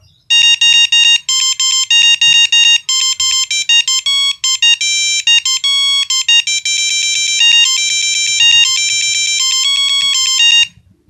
8. motorola c113 2